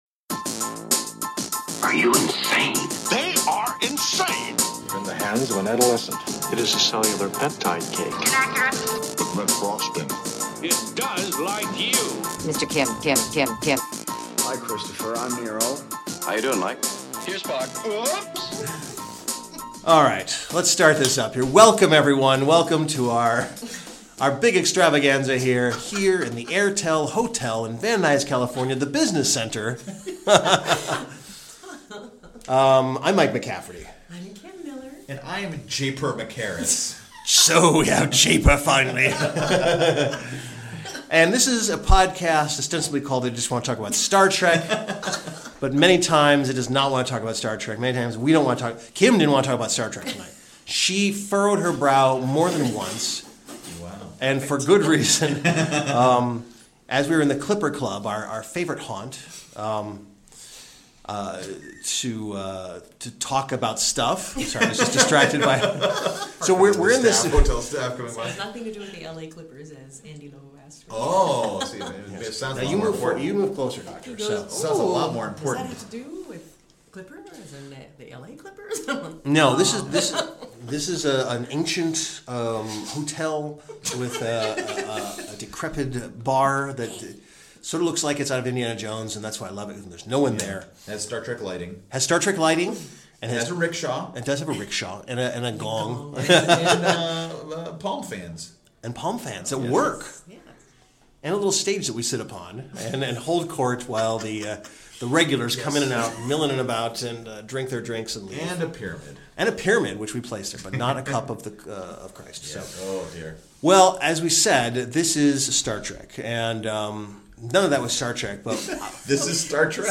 sit in a business center of an airport hotel and dissect that very year for your amusement!